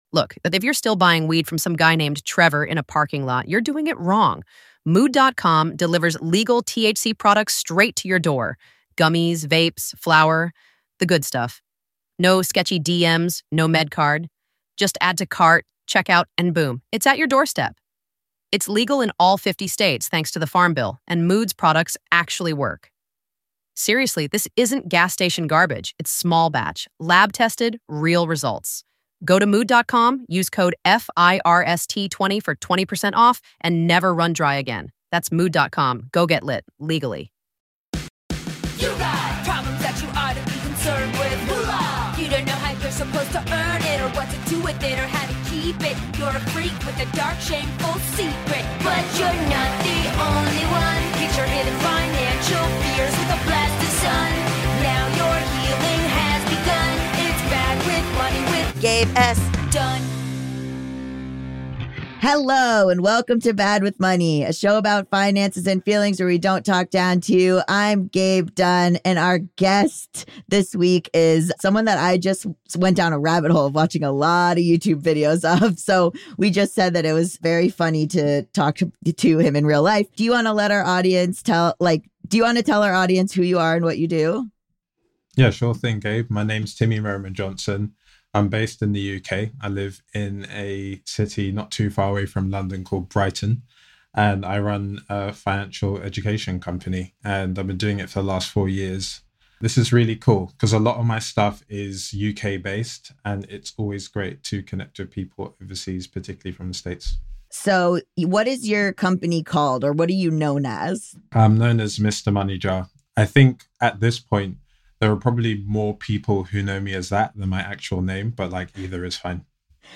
This is such a smart, in depth interview!